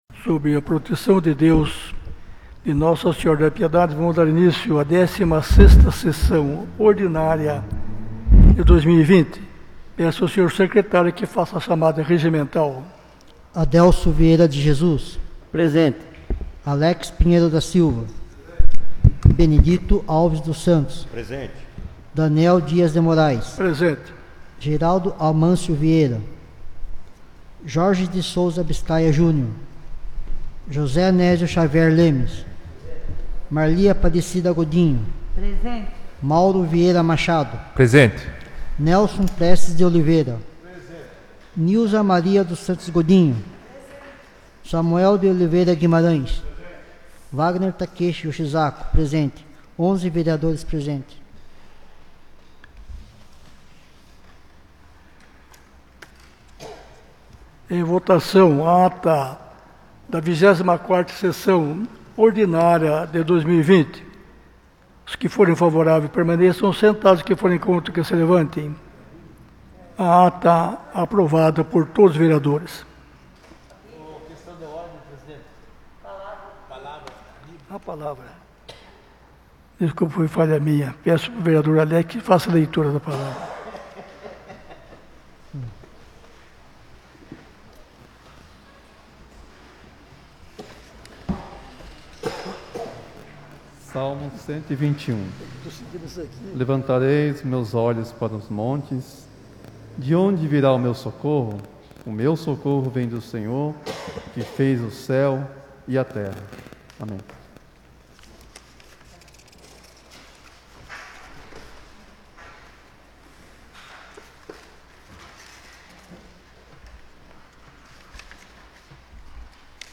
16ª Sessão Extraordinária de 2020 — Câmara Municipal de Piedade